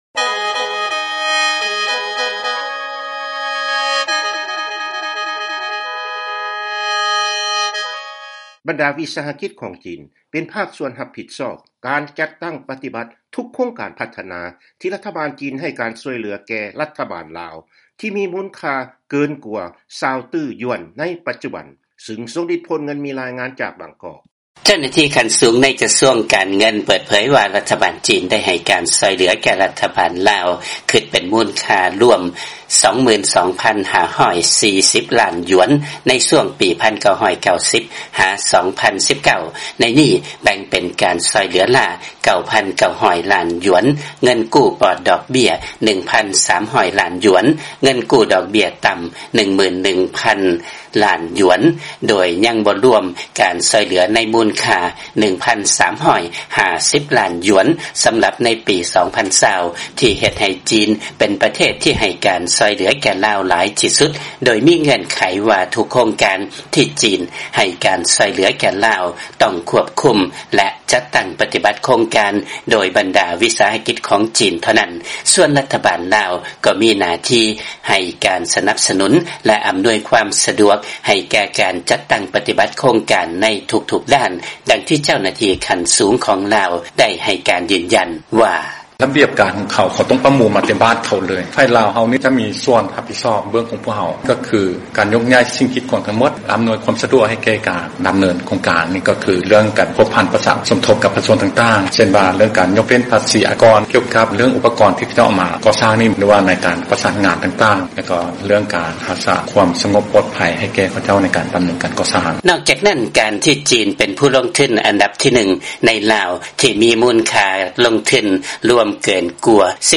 ຟັງລາຍງານ ວິສາຫະກິດຂອງ ຈີນ ຈະເປັນພາກສ່ວນ ທີ່ຮັບຜິດຊອບ ການຈັດຕັ້ງປະຕິບັດ ທຸກໂຄງການຊ່ວຍເຫຼືອຂອງ ຈີນ ໃນ ລາວ